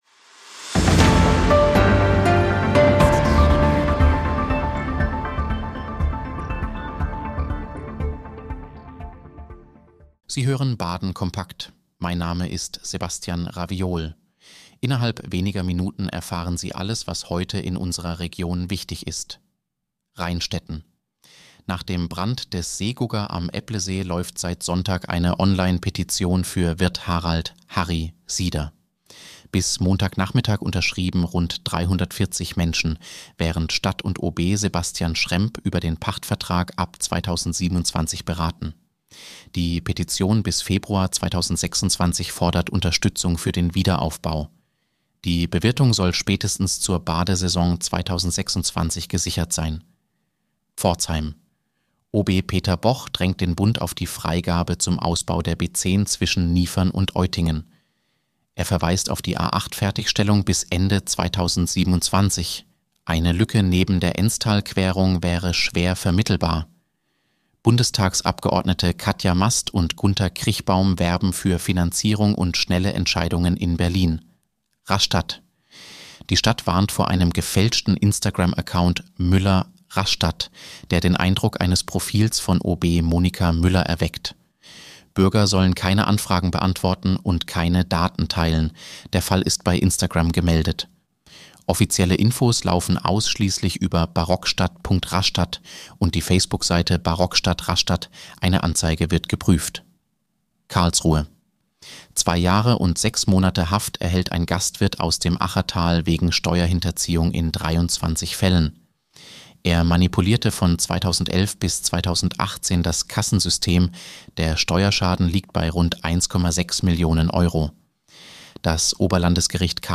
Nachrichtenüberblick Montag, 29. September 2025